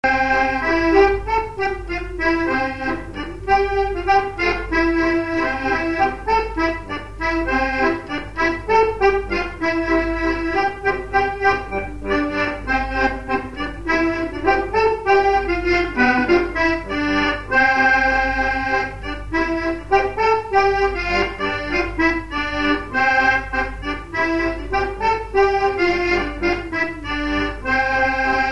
Résumé instrumental
gestuel : à marcher
circonstance : fiançaille, noce
Pièce musicale inédite